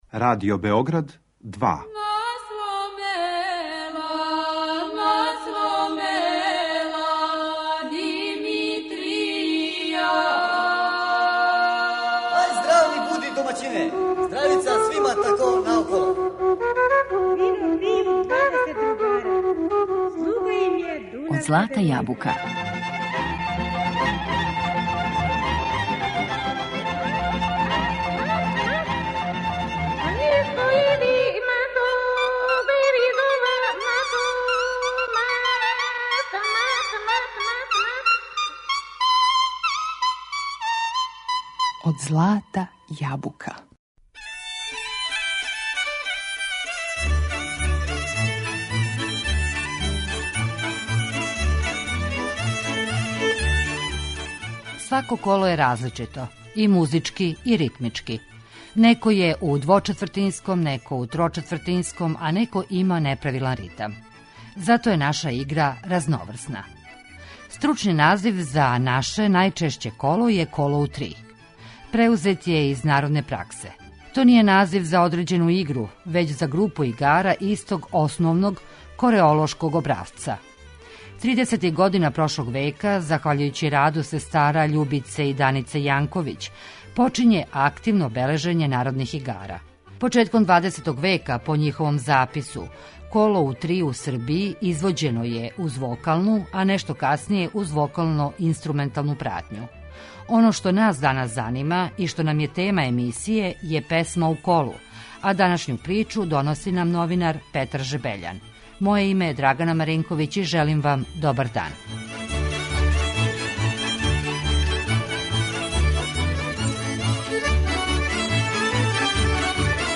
Песме које се певају у колу, тема су данашње емисије Од злата јабука. Оне имају карактеристичан ритам који одговара одређеној игри, тј. корацима којима се игра неко коло.